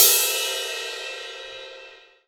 • Big Cymbal Crash Sound E Key 04.wav
Royality free crash sound clip tuned to the E note. Loudest frequency: 8011Hz
big-cymbal-crash-sound-e-key-04-P38.wav